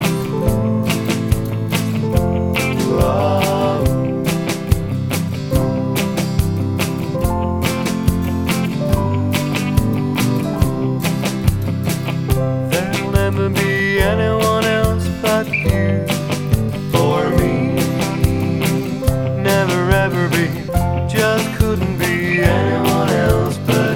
Country (Male)